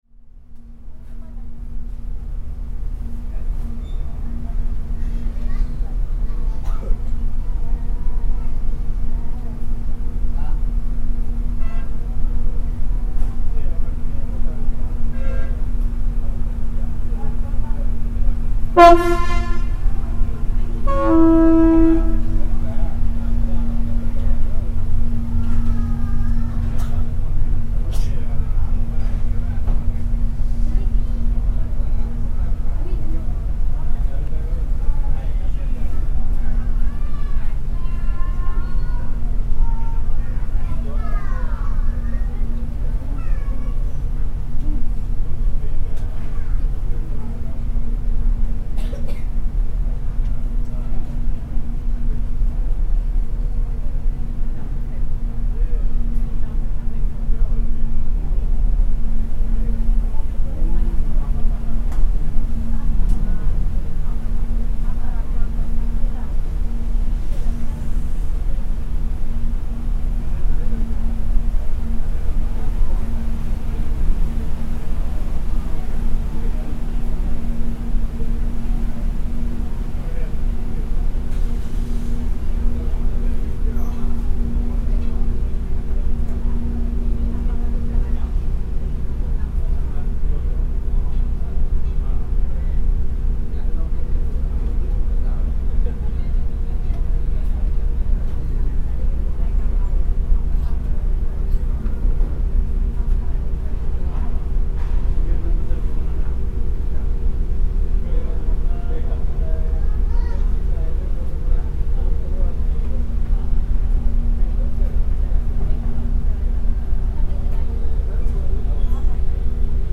This is a passenger's journey on "Pulau Undan", the name of ferry as part of the oldest ferry operation in Malaysia. This recording depicts a cross-strait transit from Butterworth to George Town on the upper deck, at the moment when the ferry approached the terminal.